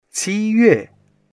qi1yue4.mp3